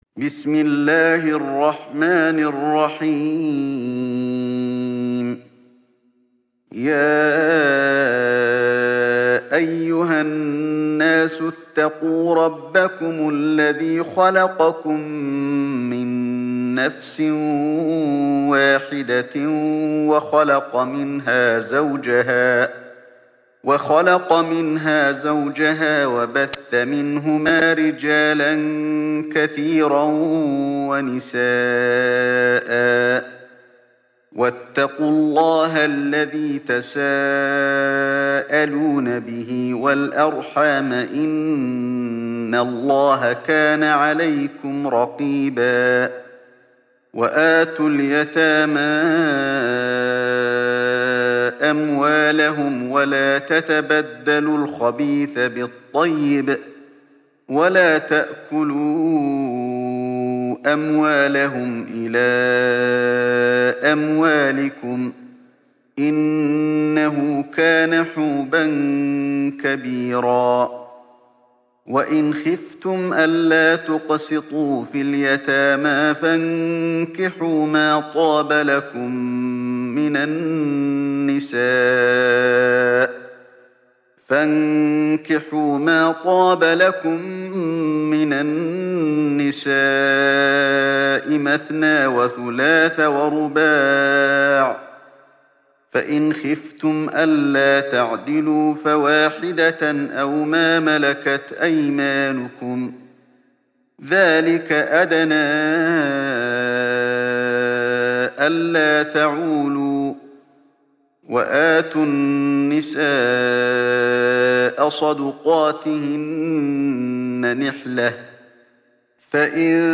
تحميل سورة النساء mp3 بصوت علي الحذيفي برواية حفص عن عاصم, تحميل استماع القرآن الكريم على الجوال mp3 كاملا بروابط مباشرة وسريعة